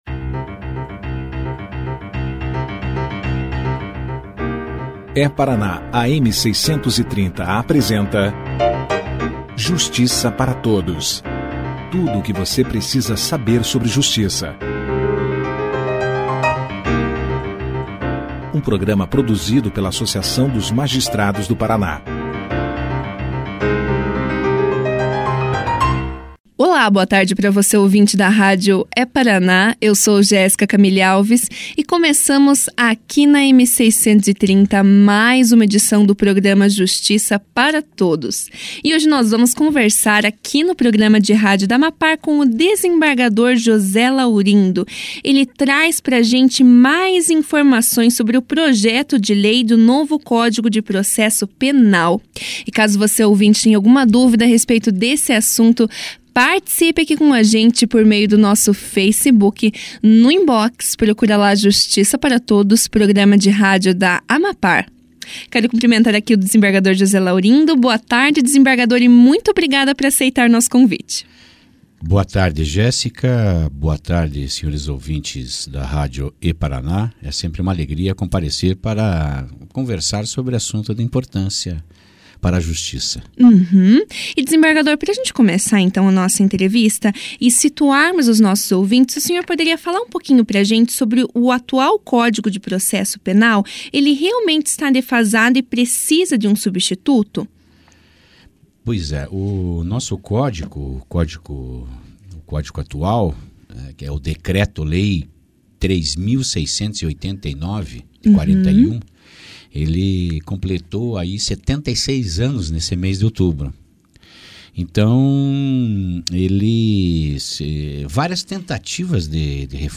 Nesta terça-feira (31), o Justiça Para Todos recebeu o desembargador José Laurindo de Souza Netto, que trouxe mais informações sobre o recente projeto de lei de reforma do Código de Processo Penal.
O desembargador especificou, ainda, as principais modificações trazidas pelo projeto do Novo CPP, e comentou sobre alguns tópicos atualmente em discussão em relação à proposta de lei, como a instituição das medidas cautelares no sistema prisional e os possíveis impactos da reforma no combate à corrupção. Confira aqui a entrevista na íntegra.